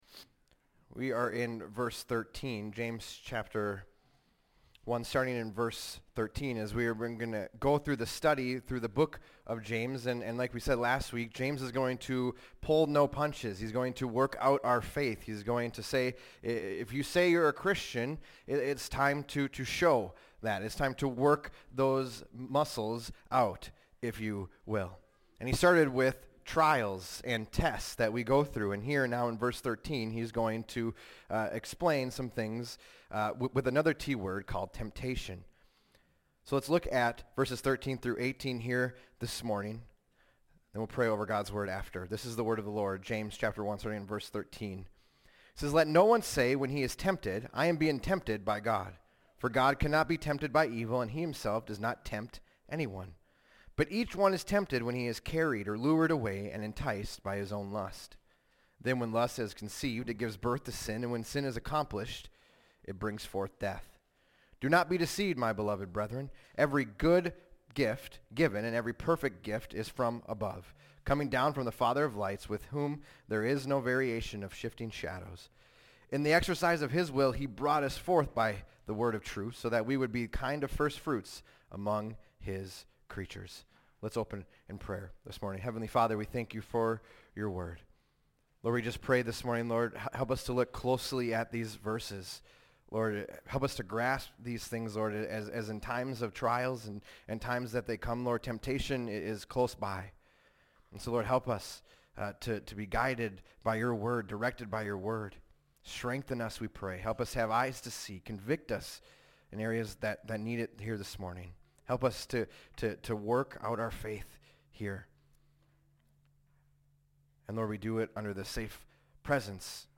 fbc_sermon_041926.mp3